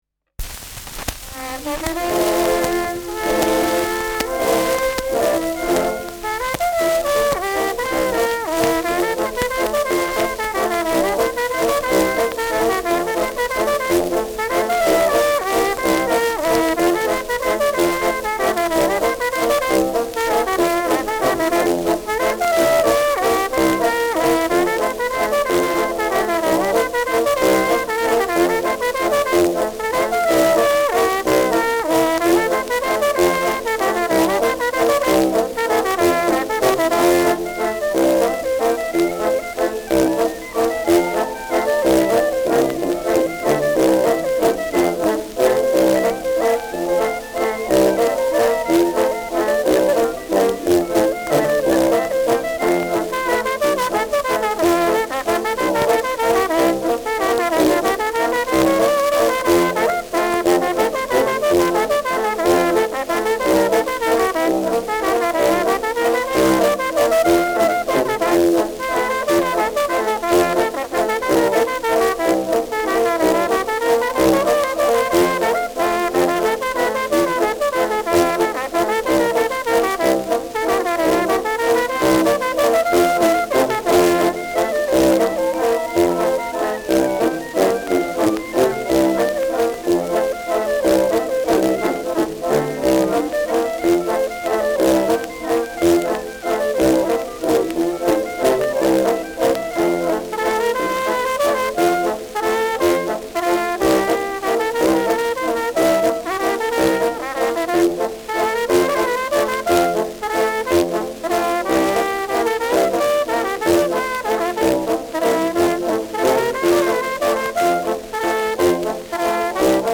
Schellackplatte
präsentes Rauschen : Nadelgeräusch zu Beginn : „Schnarren“ : abgespielt : leichtes Leiern : leichtes Knistern
Dachauer Bauernkapelle (Interpretation)